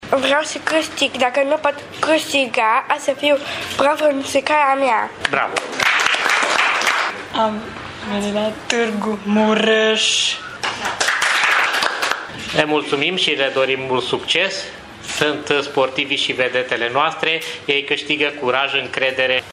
Programul manifestării a fost anunțat, astăzi, în cadrul unei conferințe de presă.
La conferința de lansare au participat și două sportive de la Special Olympics